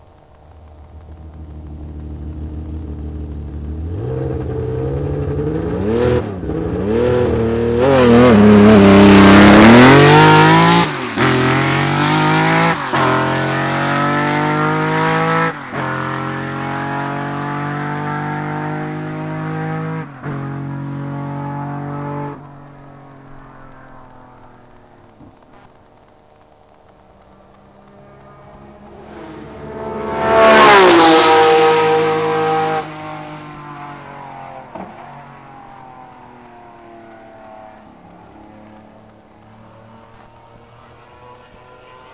- Hubraum / Bauart des Motors : 4727 ccm / V8 Zylinder
Stehender Start - Flat out - Vorbeifahrt Boxengasse
engine2-tvr-1.wav